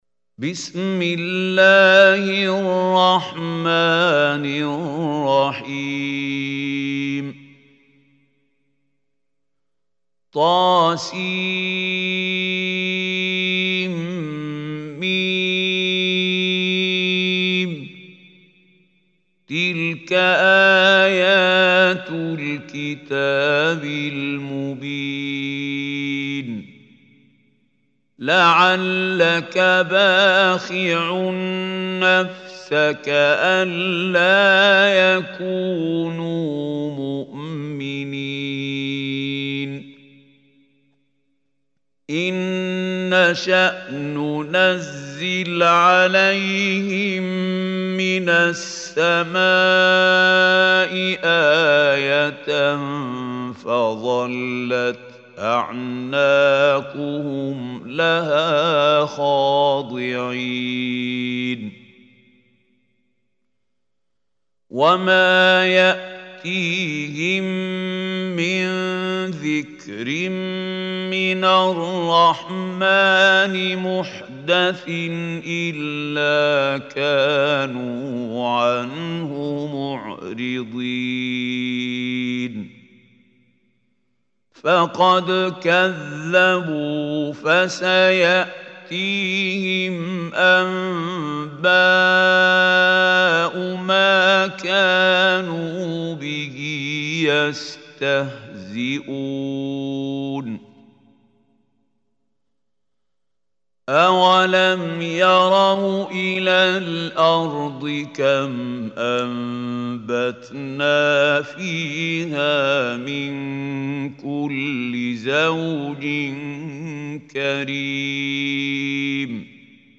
Surah Ash Shuara Recitation by Khalil al Hussary
Surah Ash-Shuara is 26th chapter of Holy Quran. Listen beautiful recitation / tilawat of Surah Ash Shuara in the voice of Mahmoud Khalil Al Hussary.